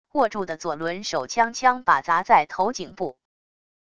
握住的左轮手枪枪把砸在头颈部wav音频